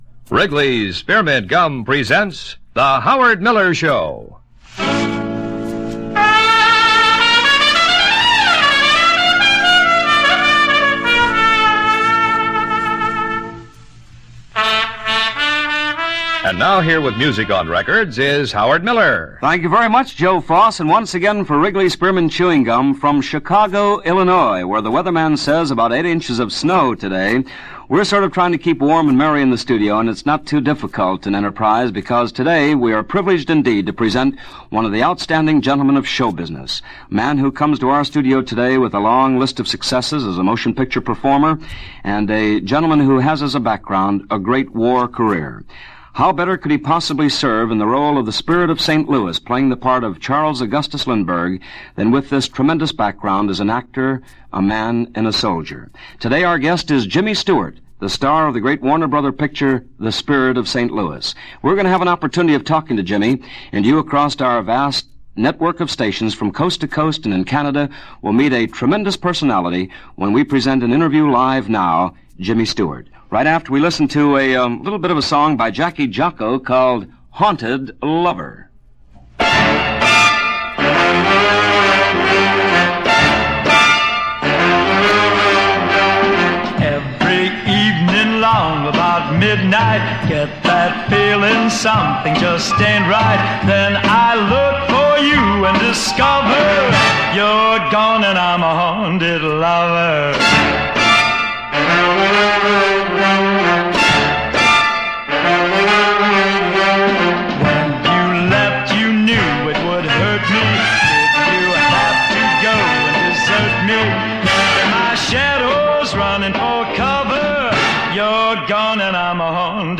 Films of the 1950s this week with the legendary Jimmy Stewart interviewed by Howard Miller for his CBS Radio series The Howard Miller Show on March 25, 1957.